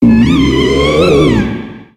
Cri de Banshitrouye dans Pokémon X et Y.